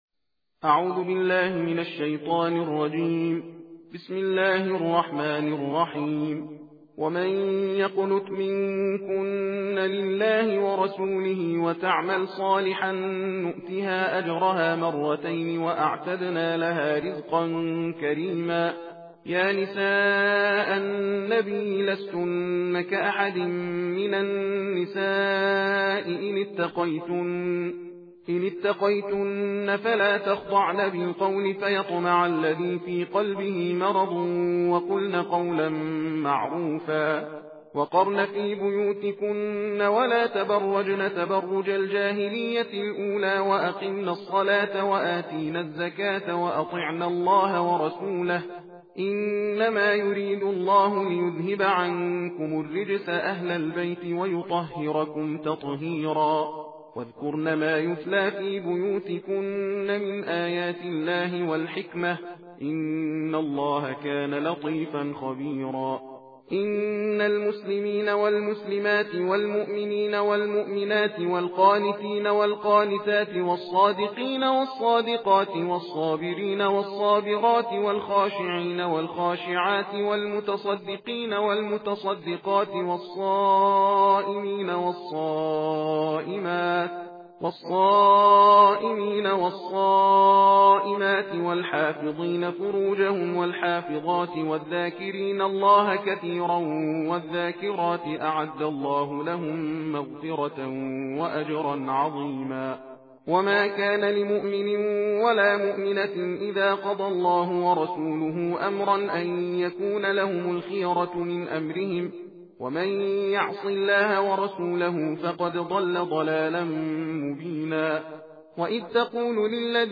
صوت/ تندخوانی جزء بیست و دوم قرآن کریم